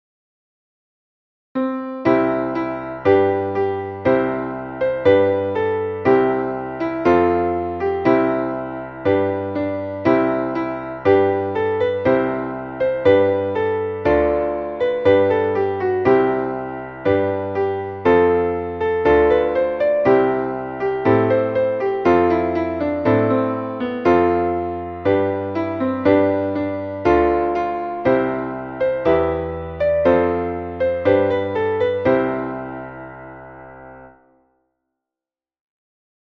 Traditionelles Neujahrslied